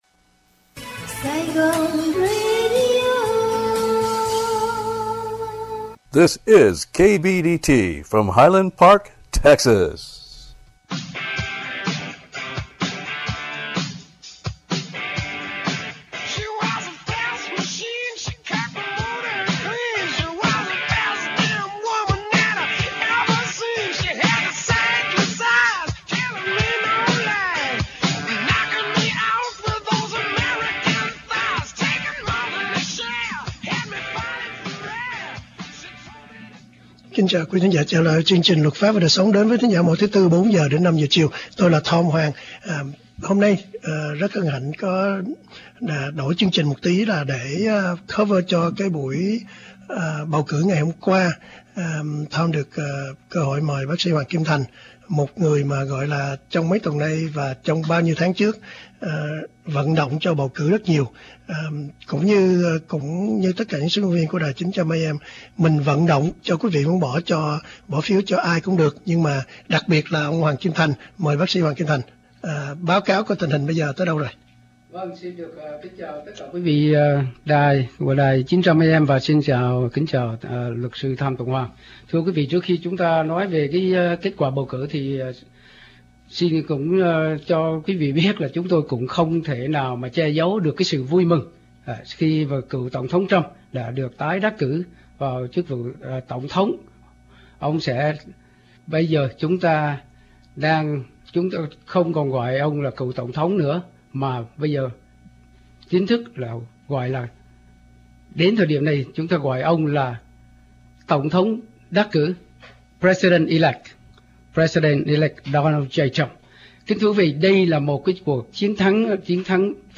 Hội luận sau bầu cử Hoa Kỳ